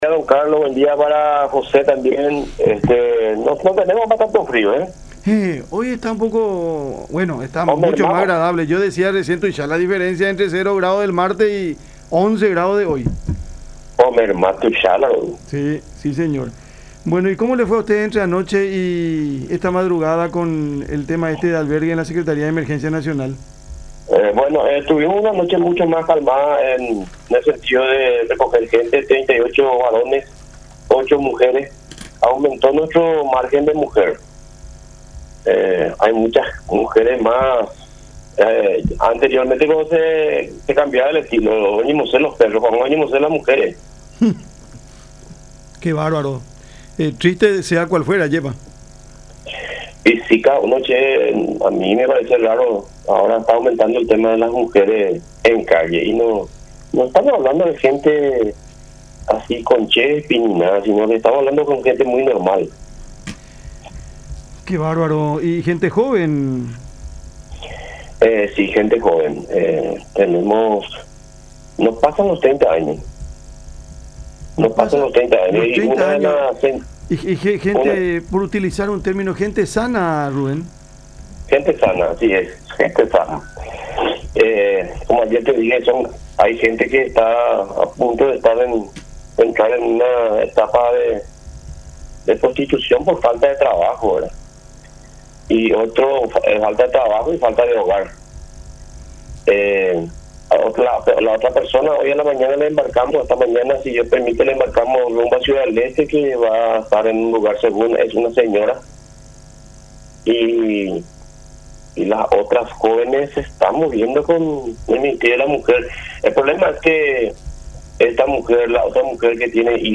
en charla con Cada Mañana por La Unión.